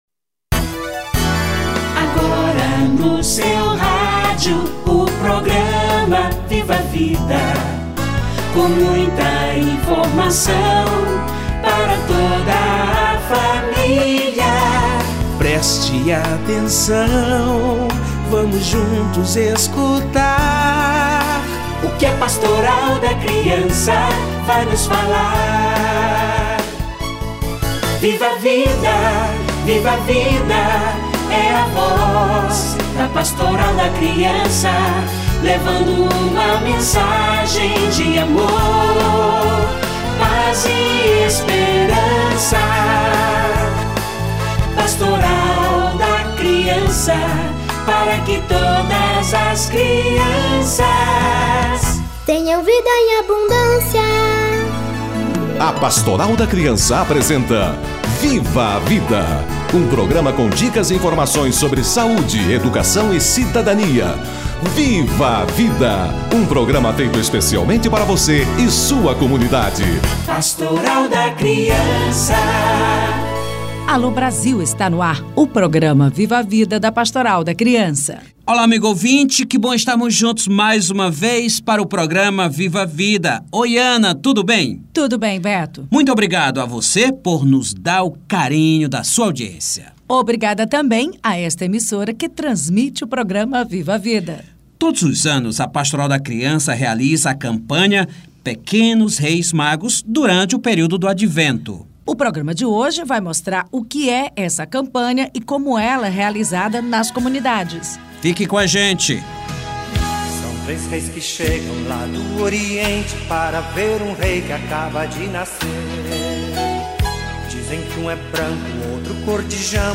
Pequenos Reis Magos - entrevista